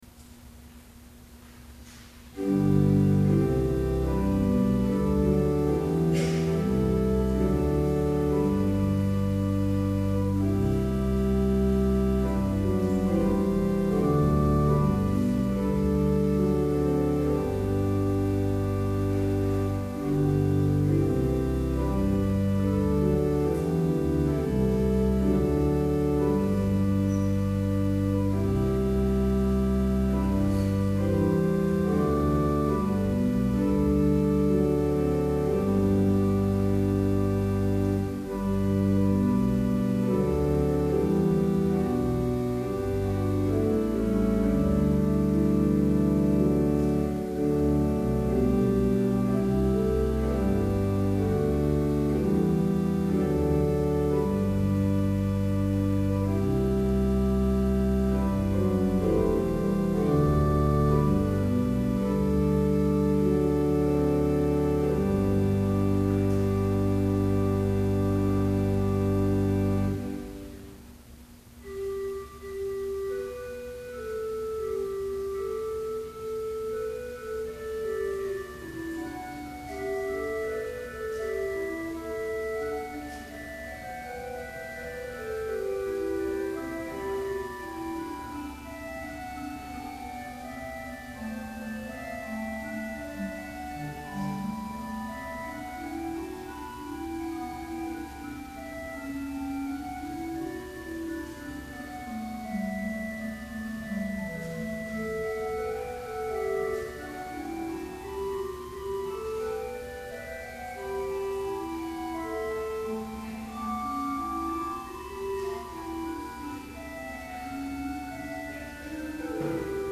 Complete service audio for Summer Chapel - June 27, 2012